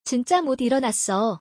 チンチャ モディロナッソ